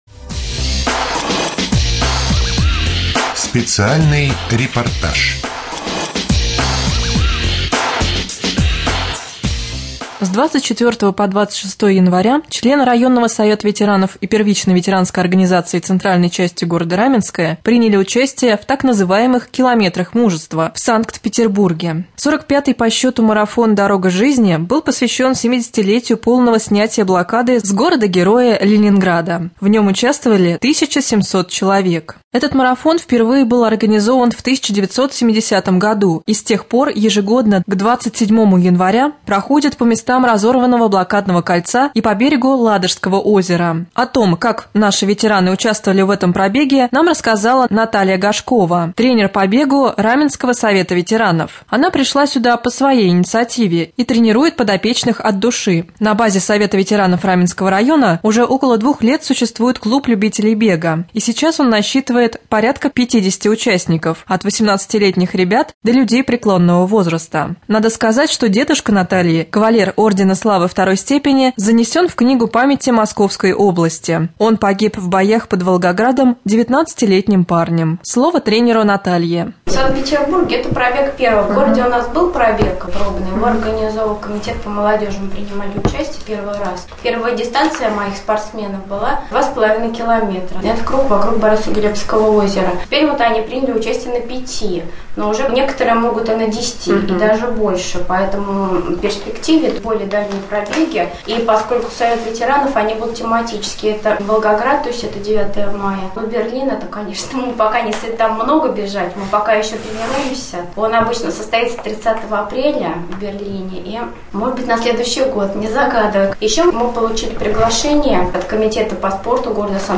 31.01.2014г. в эфире раменского радио - РамМедиа - Раменский муниципальный округ - Раменское
3. «Специальный репортаж». В январе члены районного совета ветеранов приняли участие в марафоне «Километры мужества».